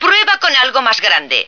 flak_m/sounds/female2/est/F2biggergun.ogg at trunk